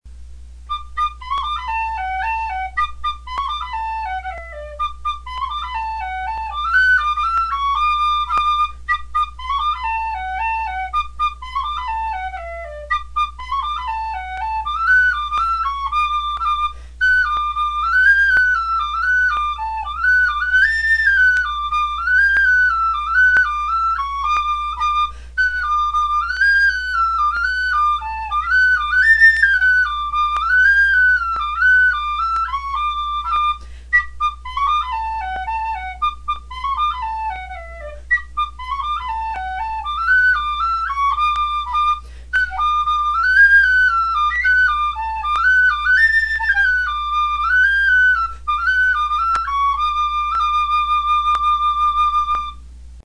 It is known as a ‘high’ whistle in the key of D, which is commonly found in Irish music.